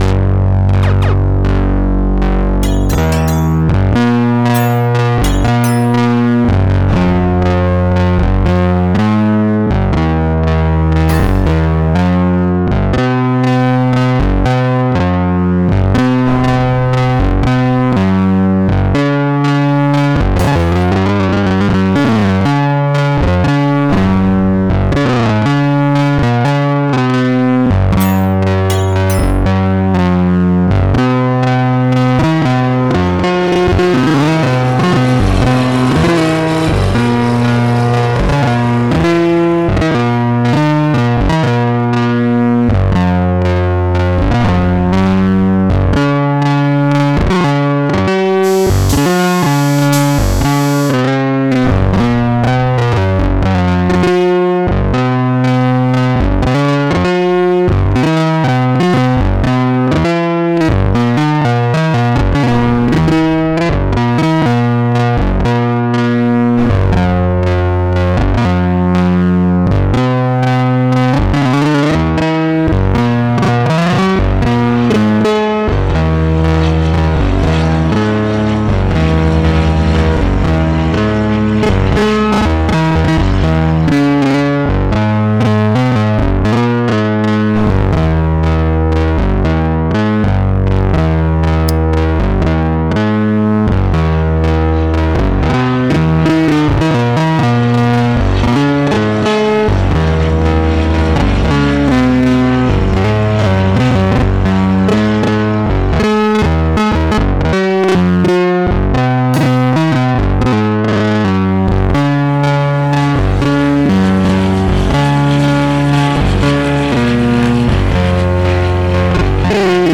256SOUND consists out of a visual and AI generated auditory artwork, derived from the data in the genesis 256 ART pieces.